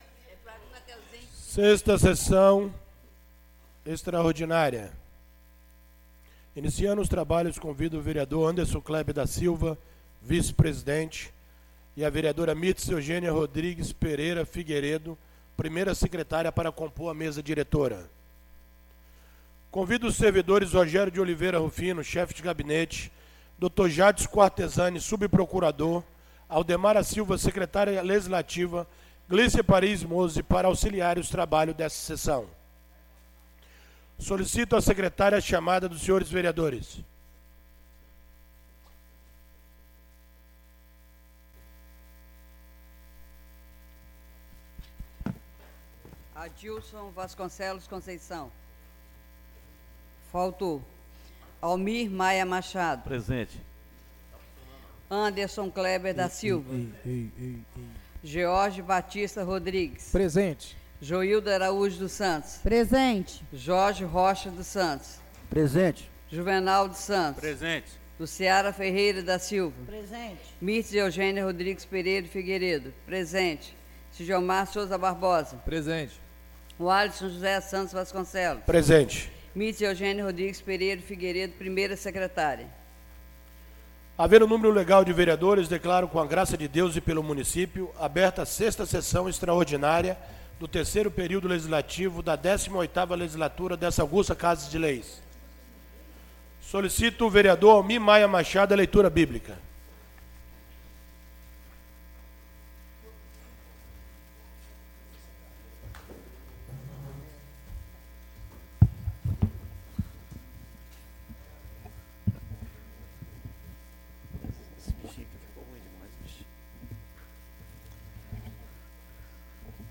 6ª Sessão Extraordinária do dia 23 de maio de 2019